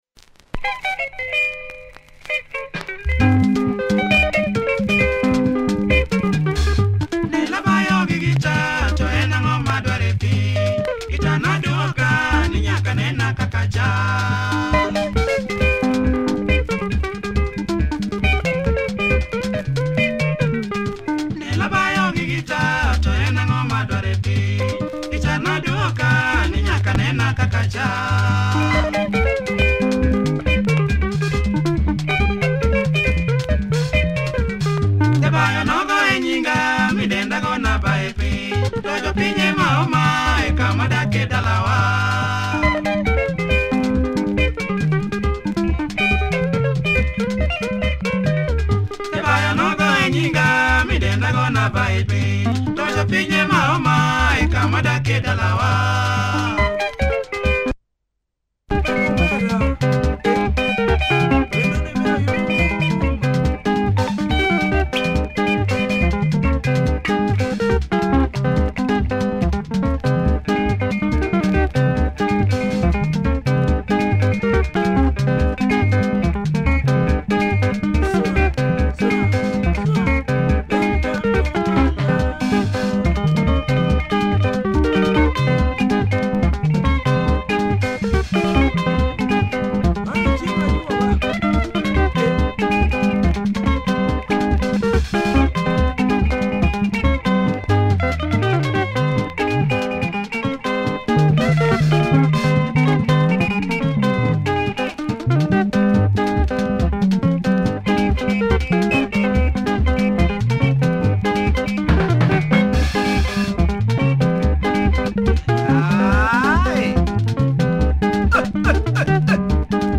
Great LUO benga